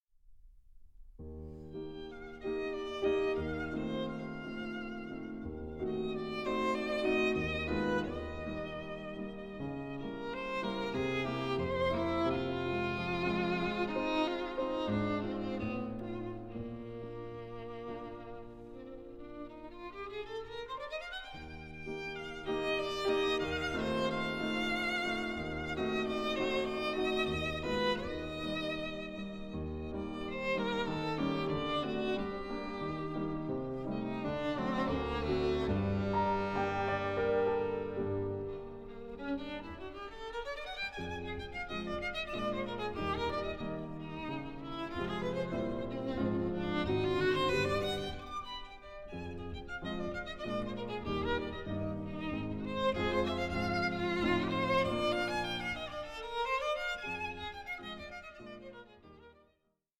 Celebrating Chamber Music
fresh and fragrant sounds